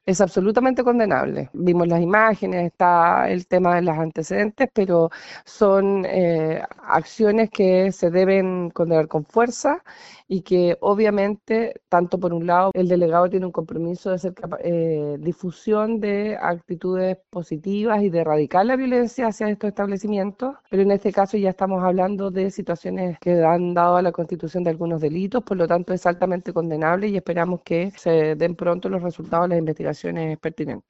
El hecho fue condenado por la seremi de Seguridad en el Bío Bío, Paulina Stuardo, quien además señaló que existe un compromiso de erradicar este tipo de hechos de los centros de salud.
cuna-seremi-seguridad.mp3